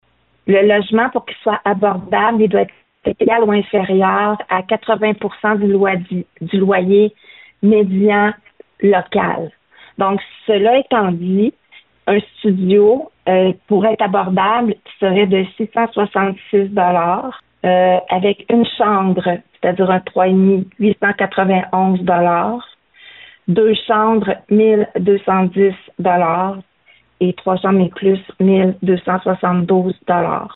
La mairesse a apporté des précisions quant à la définition de logement abordable à Bécancour.